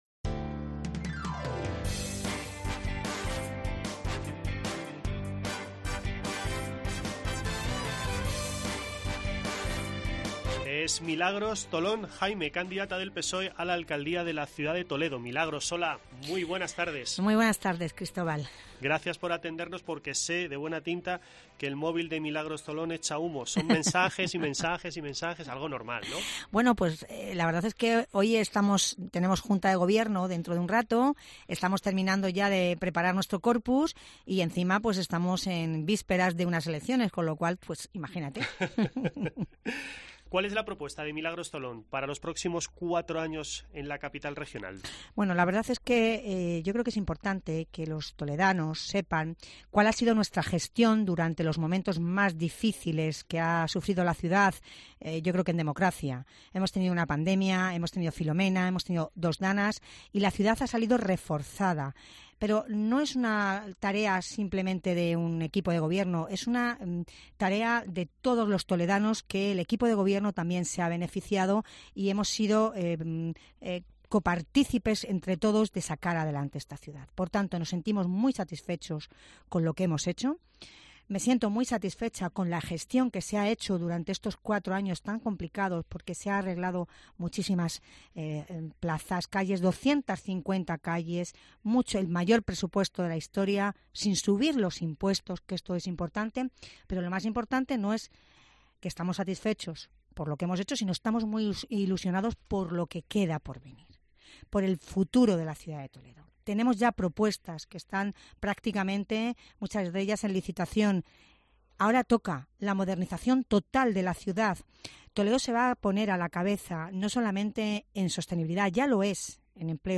Escucha en 'Herrera en COPE' la entrevista con la candidata del PSOE en la 'Ciudad Imperial' en las elecciones municipales del 28 de mayo